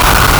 Player_Glitch [36].wav